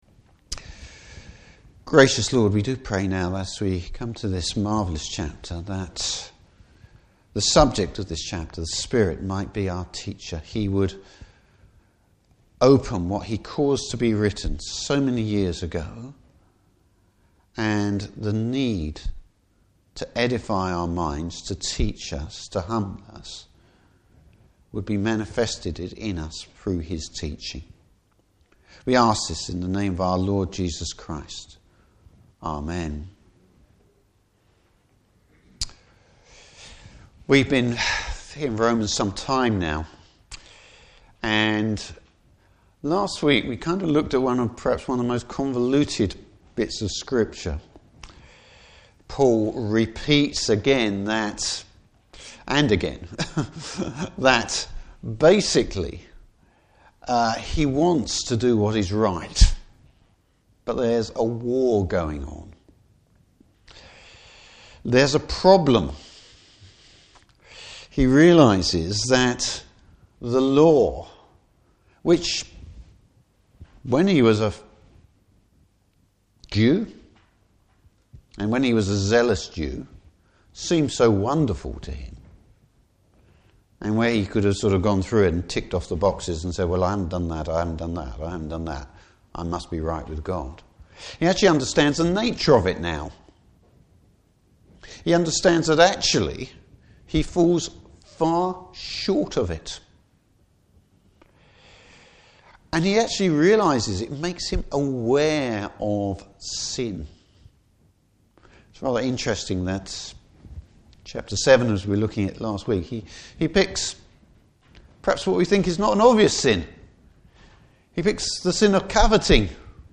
Service Type: Morning Service How the Holy Spirit is the Spirit of life.